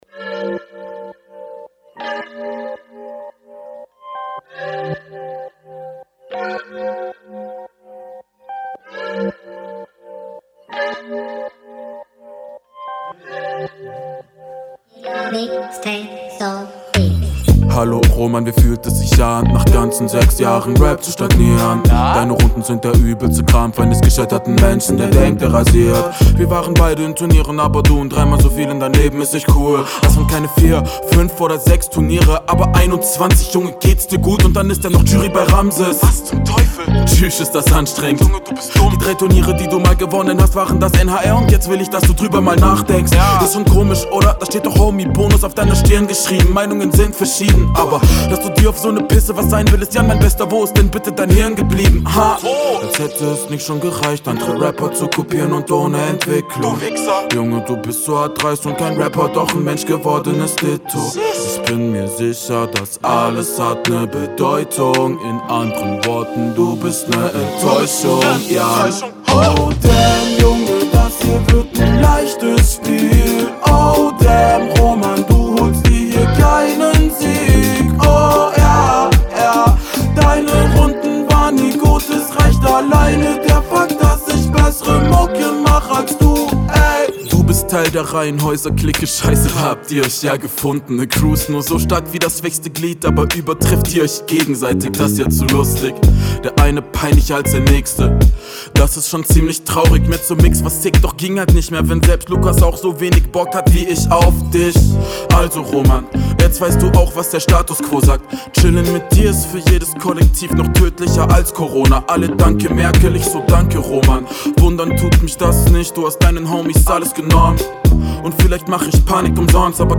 seehr smooth. schöner Einstieg wiedermal. Die leichten Gesangs-Doubles sind sehr elegant gesetzt.